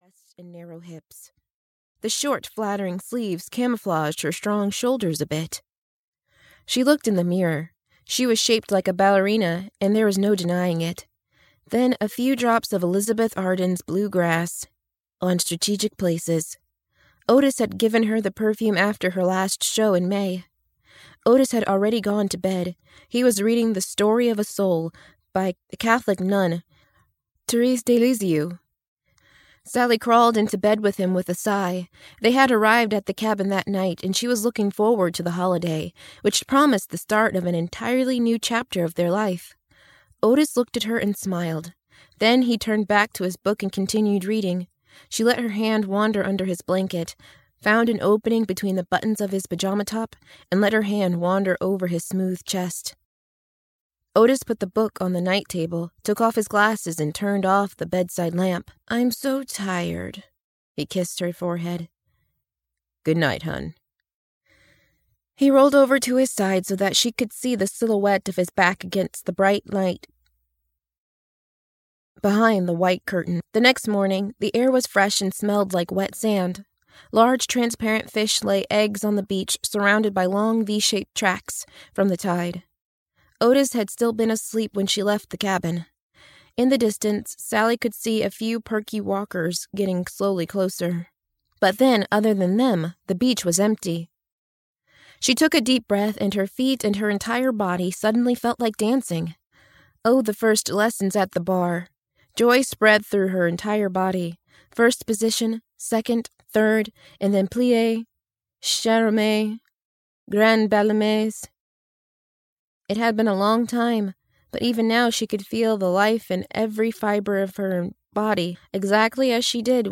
Cabin Fever 1: Written in Stone (EN) audiokniha
Ukázka z knihy